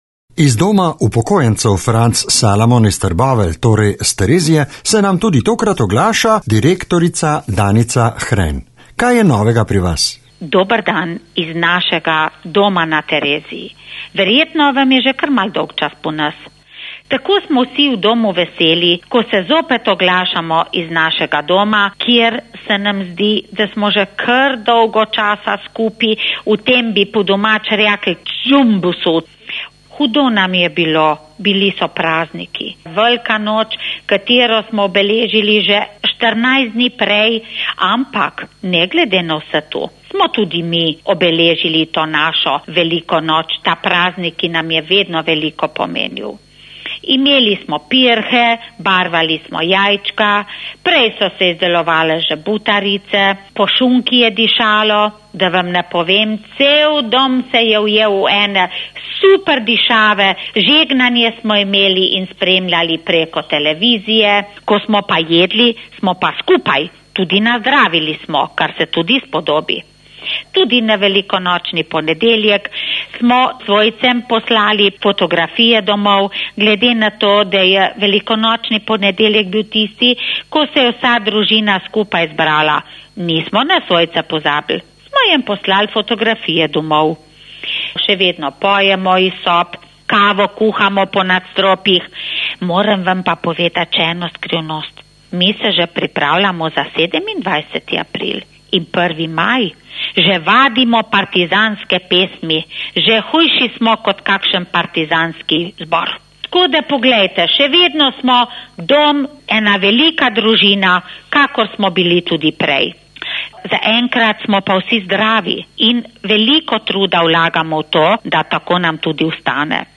O dogajanju v našem Domu objavljamo tudi na Radio Aktual Kum Trbovlje. V posnetku lahko poslušate novice iz Doma na Tereziji
po telefonu